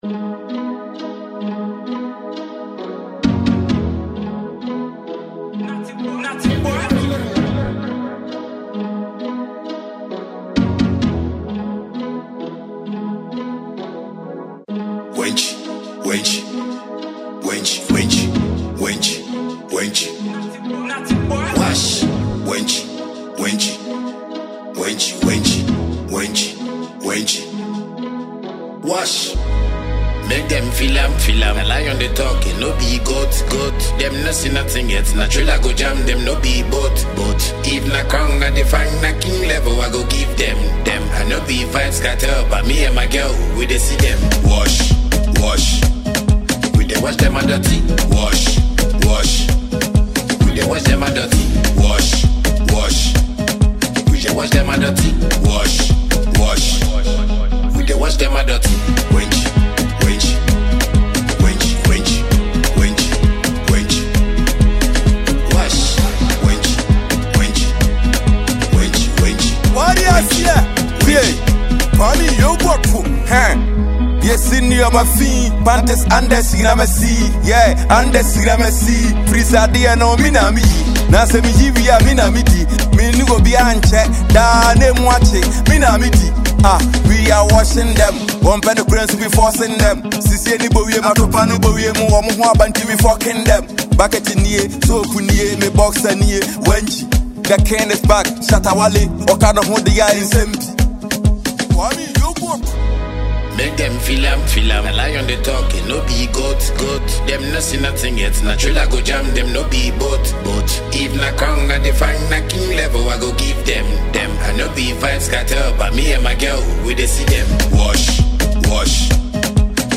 a danceable tune for all.